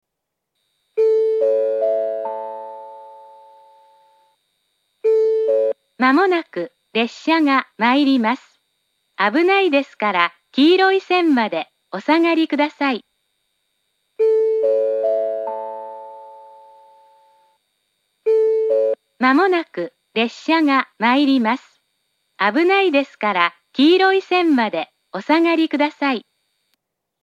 j-village-2bannsenn-sekkinn.mp3